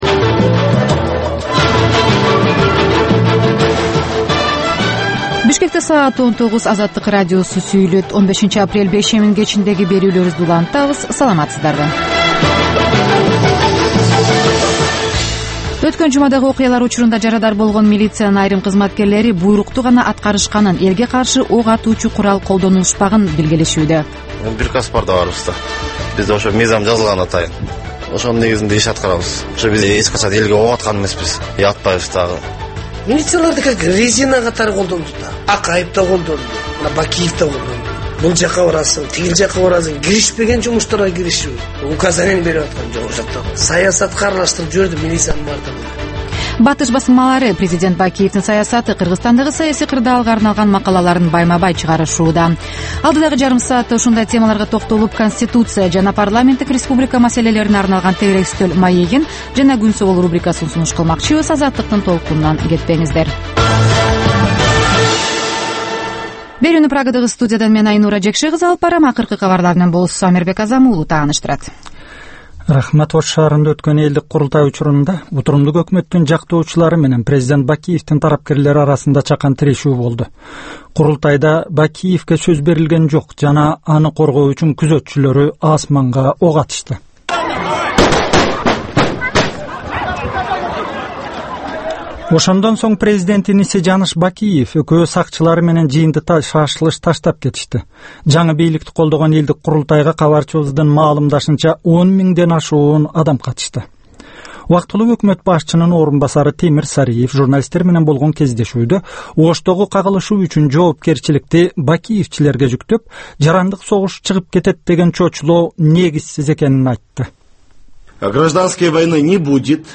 "Азаттык үналгысынын" бул кечки алгачкы берүүсү (кайталоо) жергиликтүү жана эл аралык кабарлардан, репортаж, маек, баян жана башка берүүлөрдөн турат.